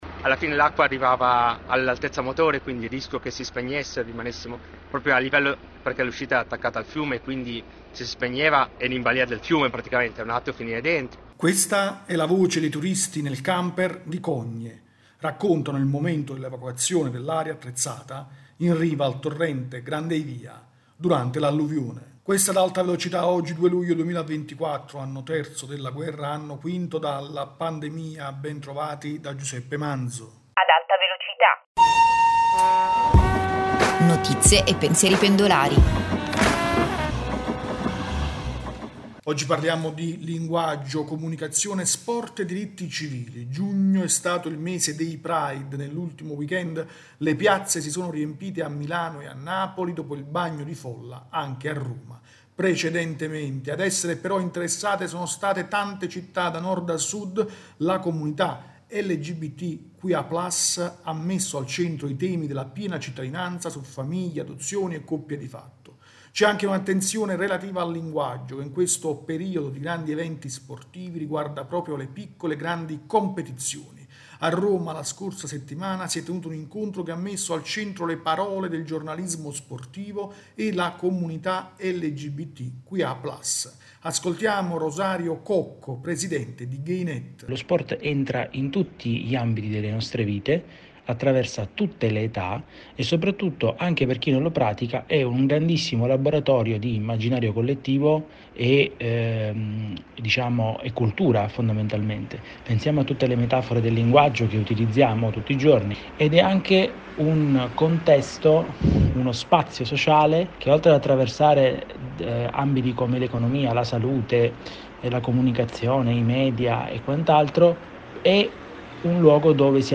[Apertura: Questa è la voce dei turisti in camper a Cogne: raccontano il momento dell’evacuazione dall’area attrezzata in riva al torrente Grand Eyvia.
rubrica quotidiana